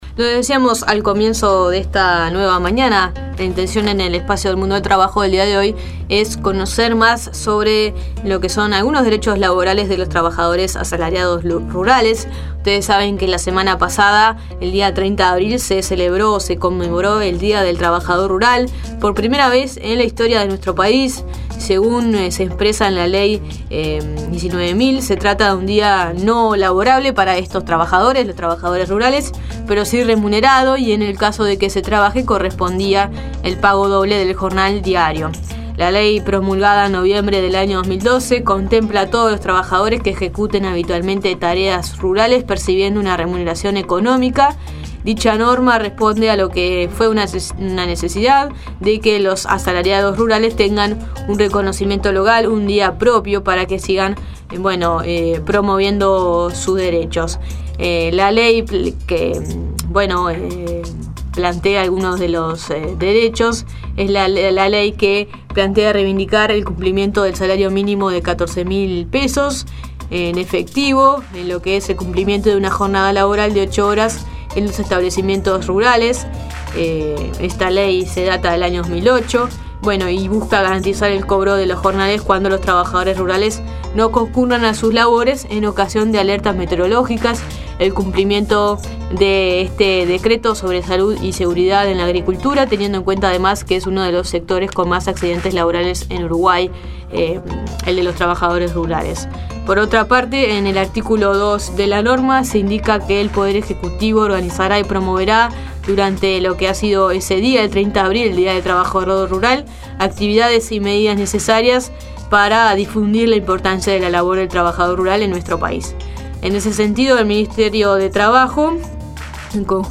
Audio: Trabajadores Rurales en Uruguay. Entrevista a Eduardo Pereyra.
Para saber más detalles, en La Nueva Mañana hablamos con Eduardo Pereyra, Director Nacional de Empleo.